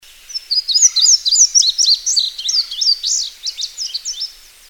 Indigo Bunting (Passerina cyanea)
Indigo Bunting song(74 Kb mp3 file) The bird was recorded May 13, 2003 at Higbee Beach, Cape May, New Jersey.